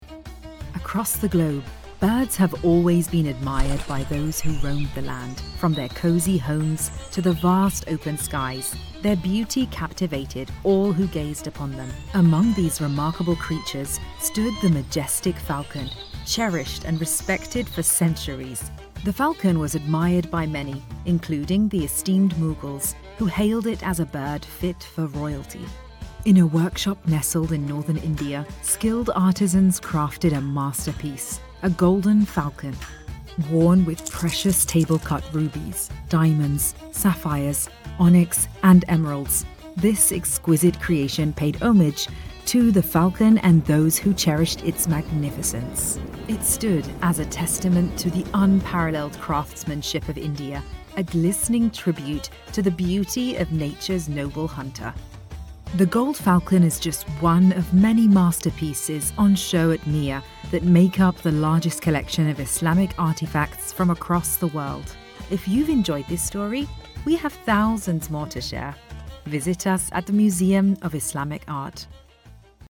Profonde, Naturelle, Distinctive, Chaude, Douce
Guide audio